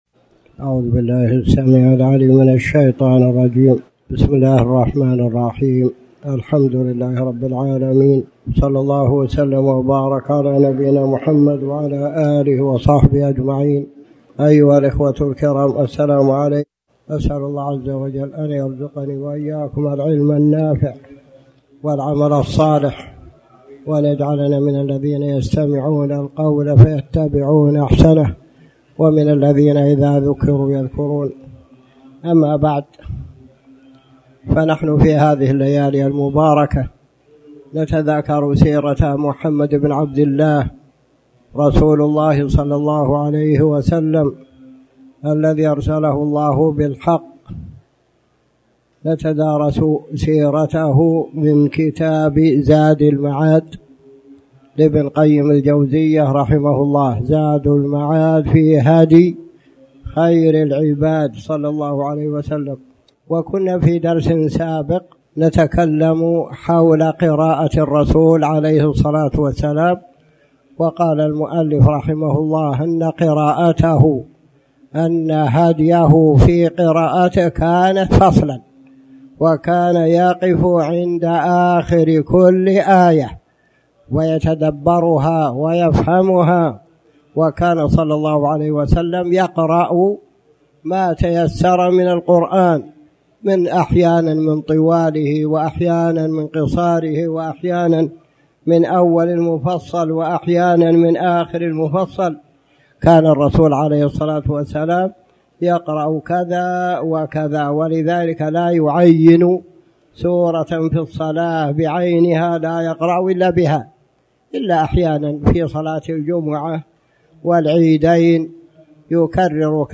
تاريخ النشر ٢٢ رجب ١٤٣٩ هـ المكان: المسجد الحرام الشيخ